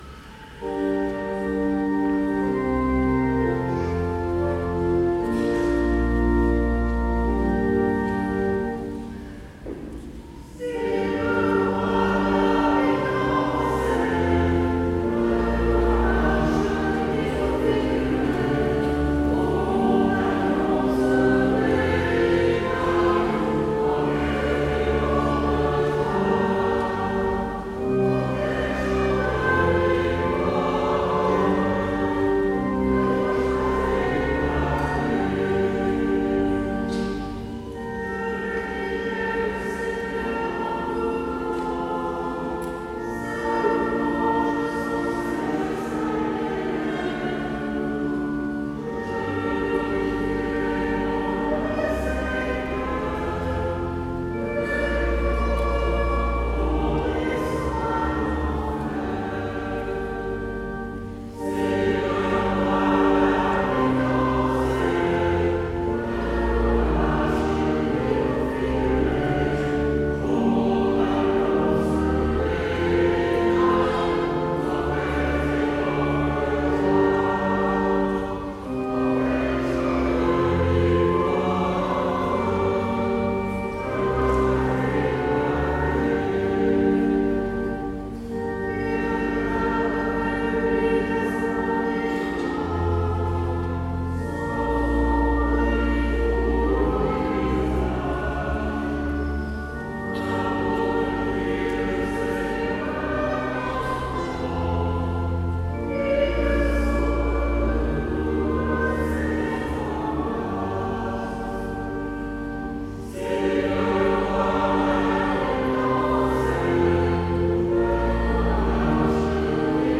Si-le-roi-David-dansait-Tutti.mp3